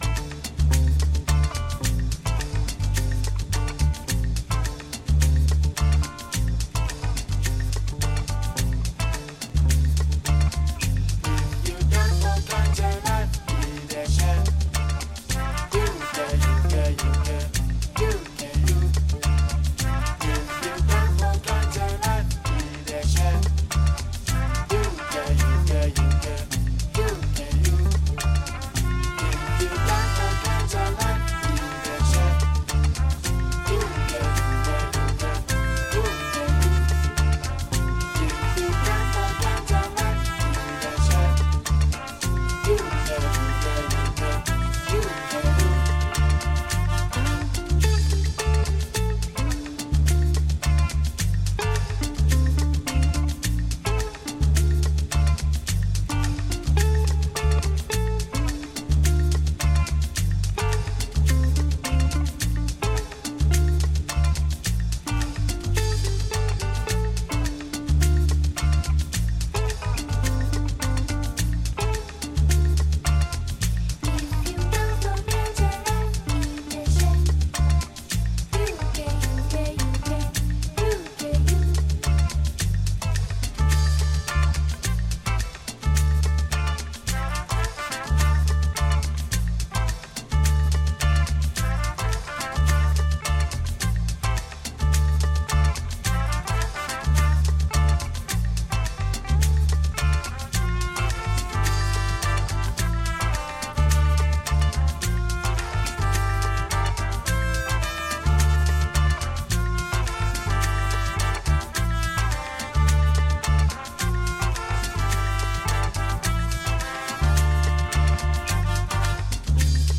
Enjoy the African rhythms and sun drenched music!